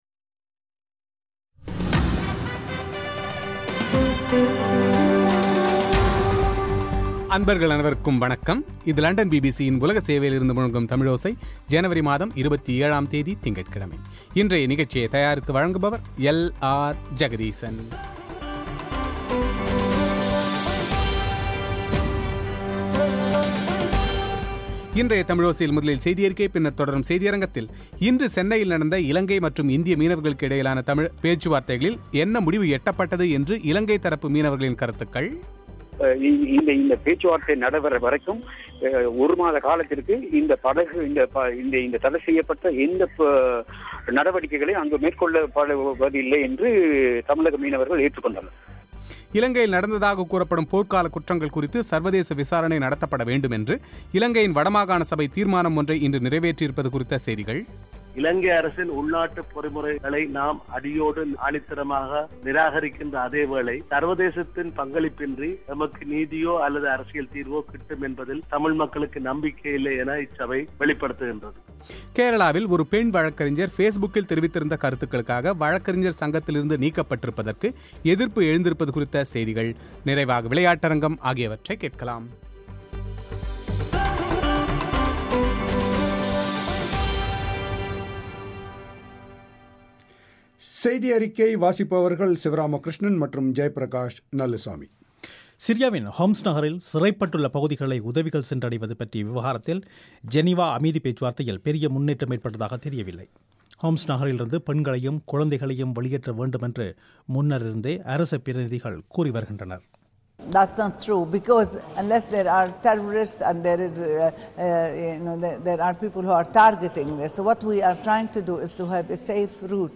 பிபிசி தமிழோசைக்கு அளித்த பிரத்யேக செவ்வி